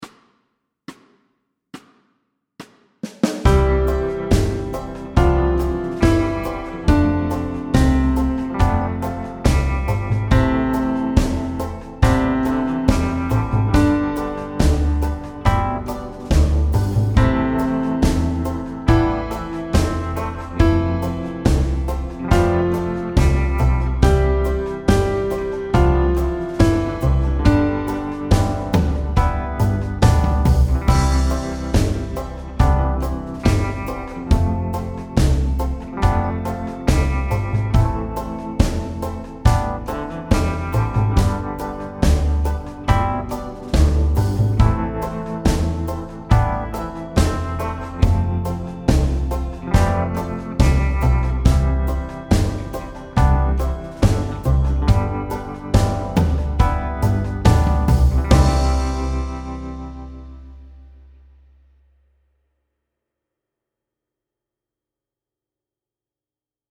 Medium C instr (demo)